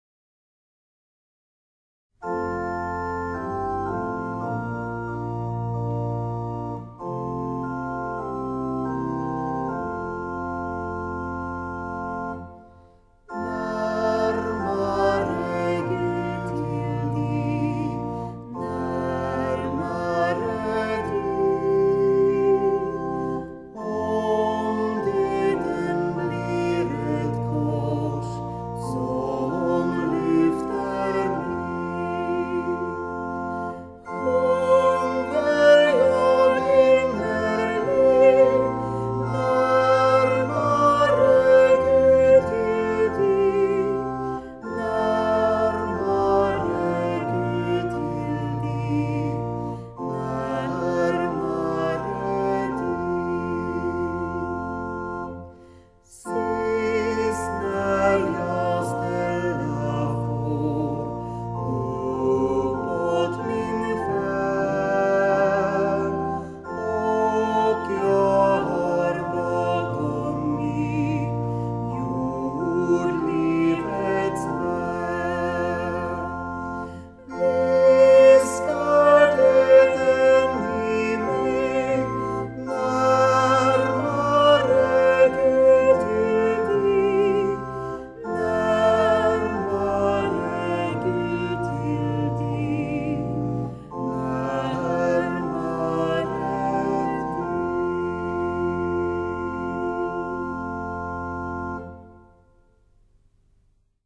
Psalmer vid begravning
Här kan du lyssna på ett urval av psalmer som församlingens musiker spelar.